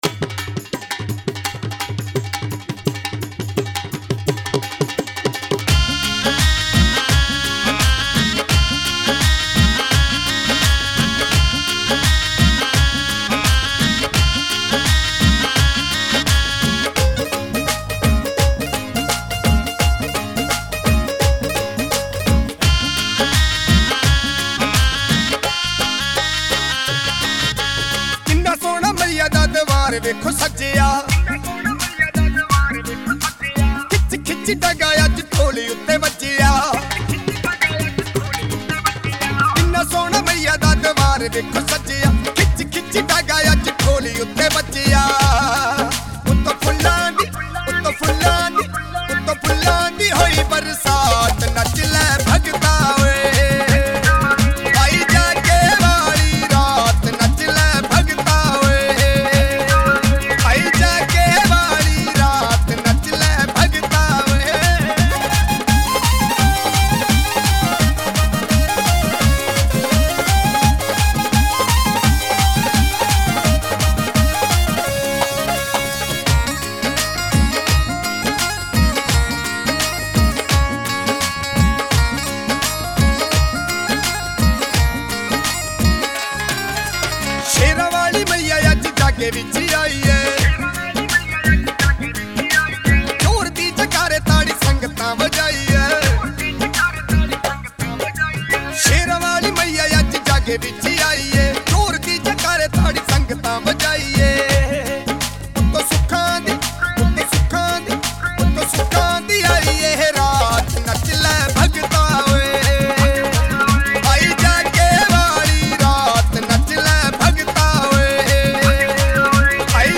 Category: Bhakti Sangeet